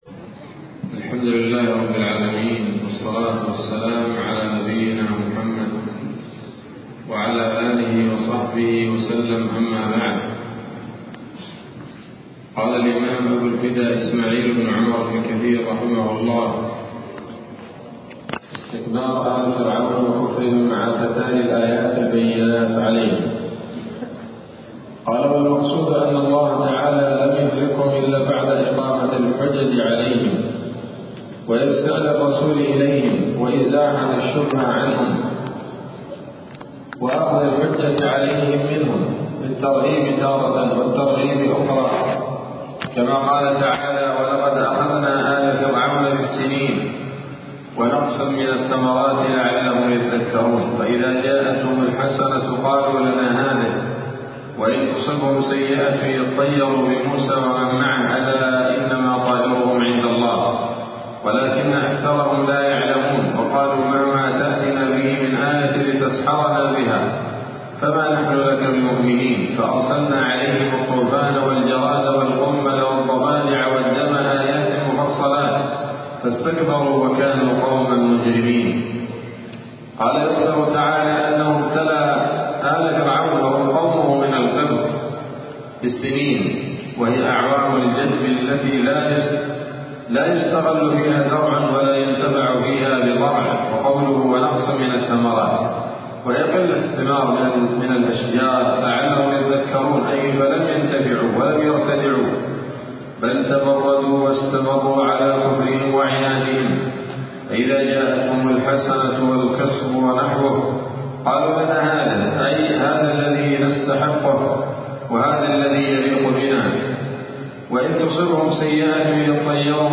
‌‌الدرس الحادي والتسعون من قصص الأنبياء لابن كثير رحمه الله تعالى